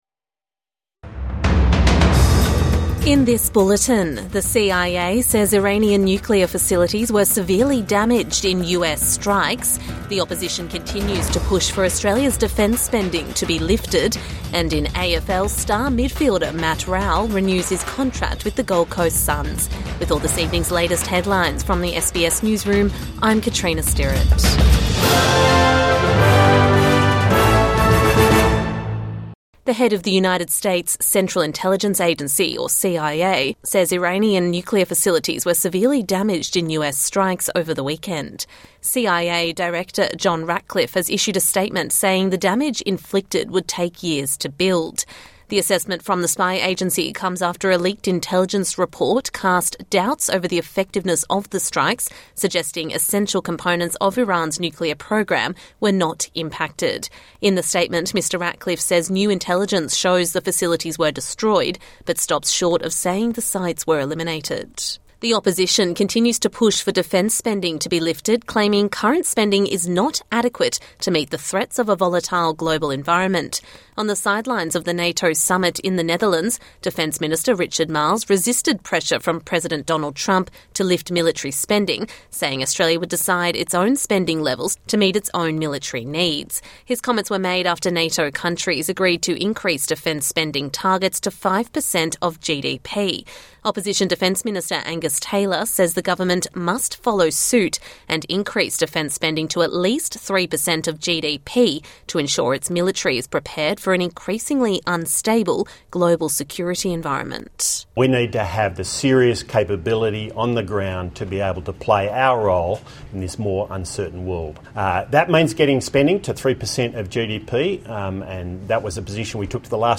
Evening News Bulletin